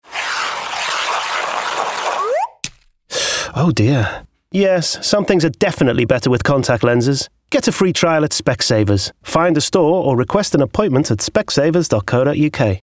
40/50's Northern/Liverpool,
Warm/Natural/Reassuring